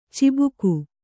♪ cibuku